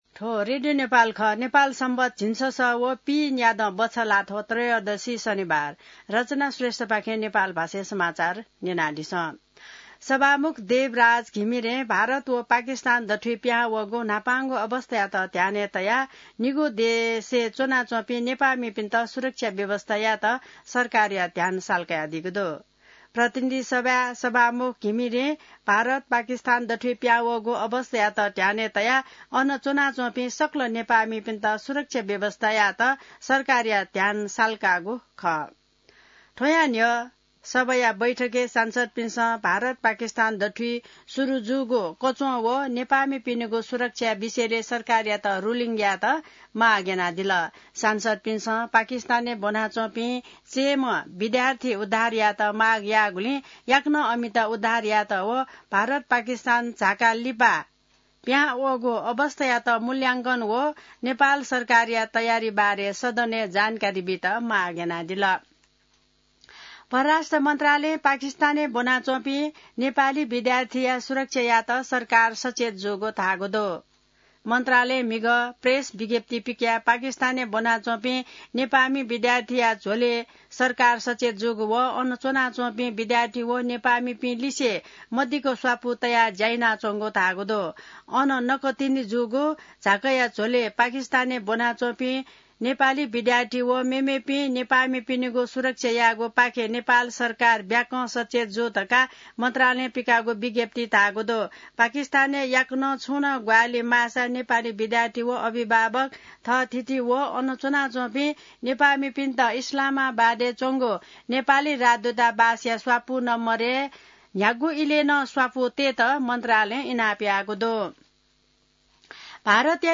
नेपाल भाषामा समाचार : २७ वैशाख , २०८२